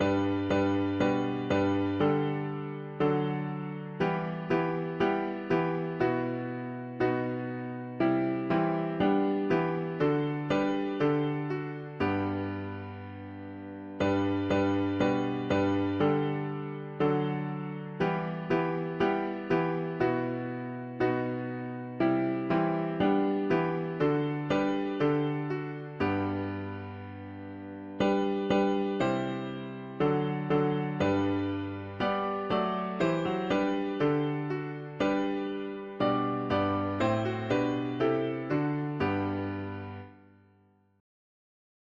Alles in uns schweige und sich innigst vor ihm beuge…. german christian 4part chords
Key: G major